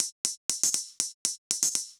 Index of /musicradar/ultimate-hihat-samples/120bpm
UHH_ElectroHatA_120-04.wav